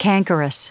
Prononciation du mot cankerous en anglais (fichier audio)
cankerous.wav